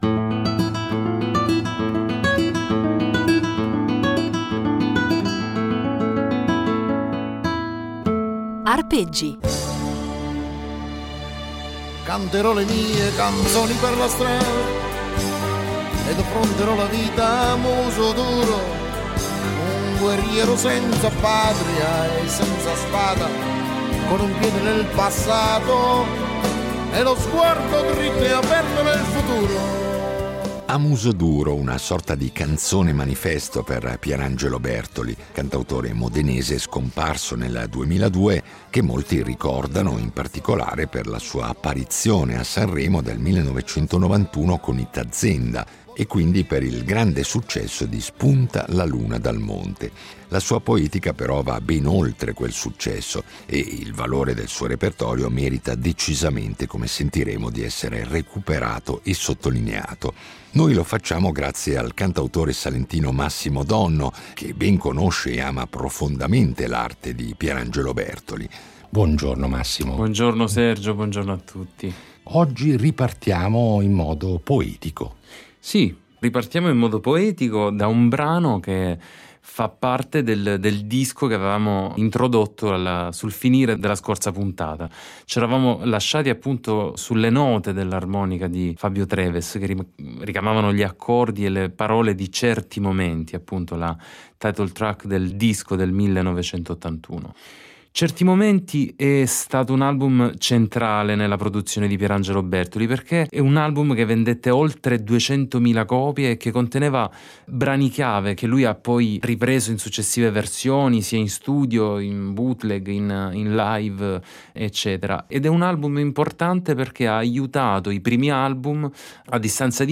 chitarra
violoncellista